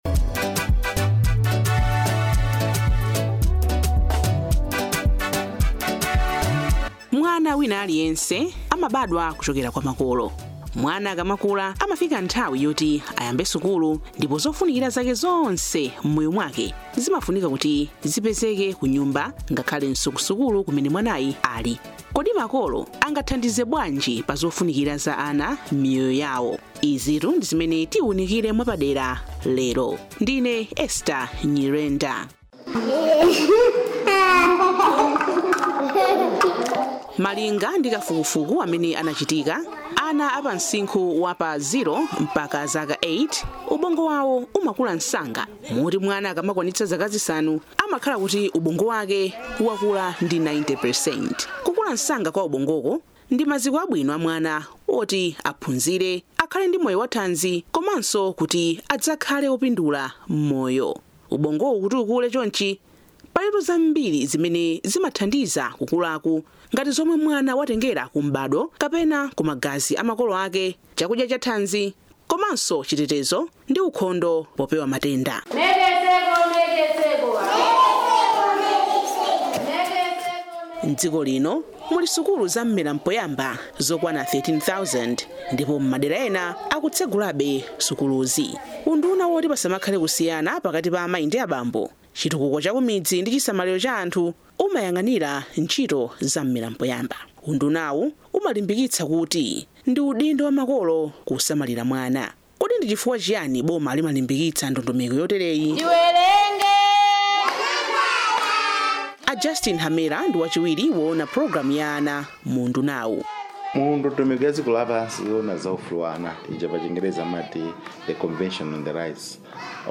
Documentary on Positive Parenting